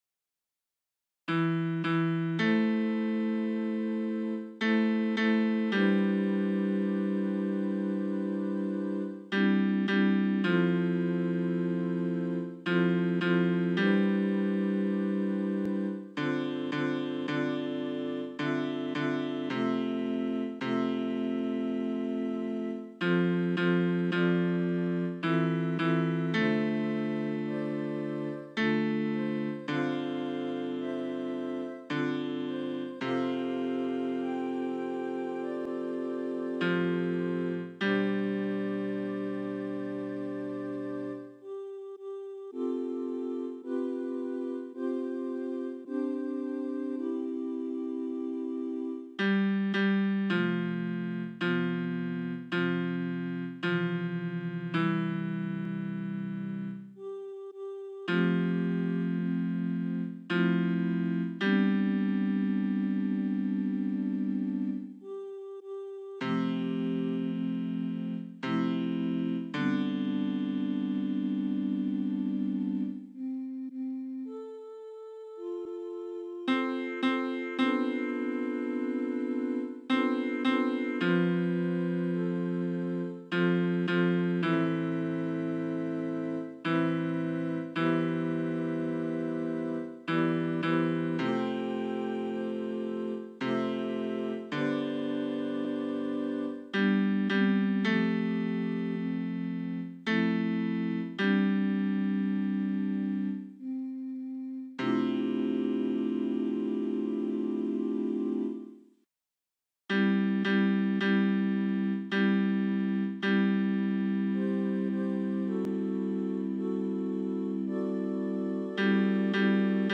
Versions piano
BASS 1